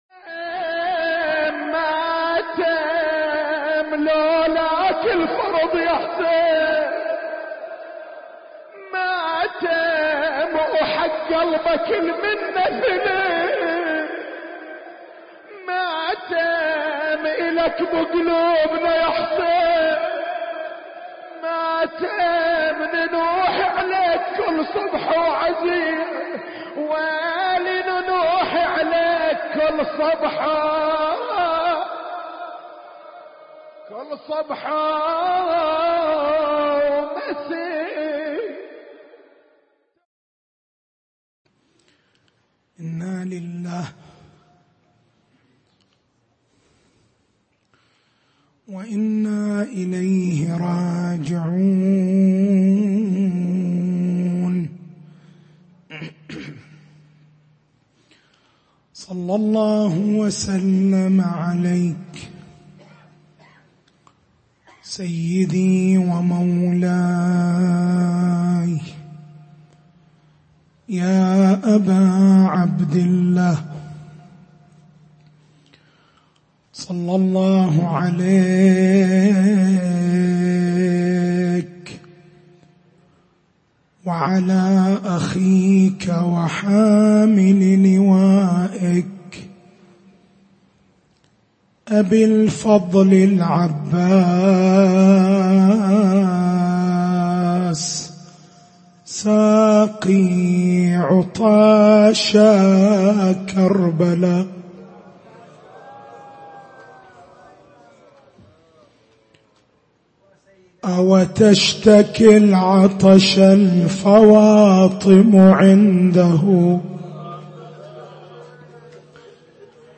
تاريخ المحاضرة: 07/01/1441 نقاط البحث: مفهوم البصيرة الفرق بين العقل النظري والعقل العملي لماذا فقدان البصر أهون من فقدان البصيرة؟
حسينية بن جمعة بالكويكب